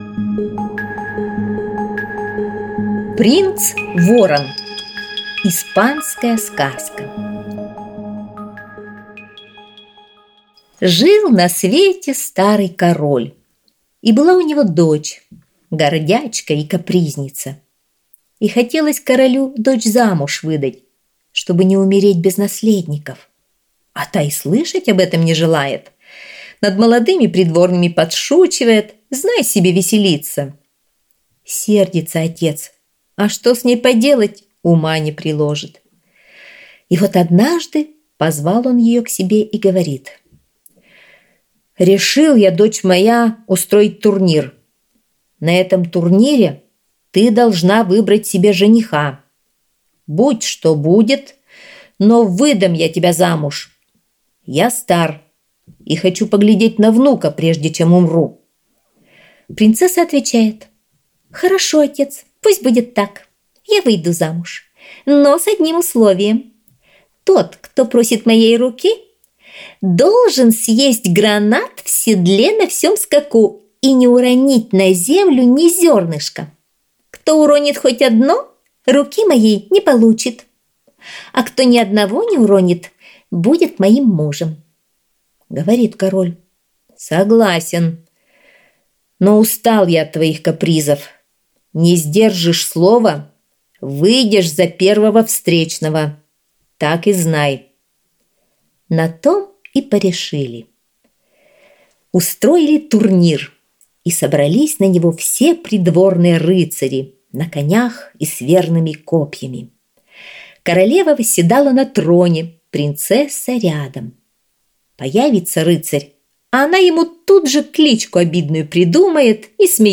Принц-ворон - испанская аудиосказка. У одного короля была дочь гордячка и капризница. Король хотел выдать её замуж, но принцесса и слышать об этом не хотела.